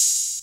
SG - Open Hat.wav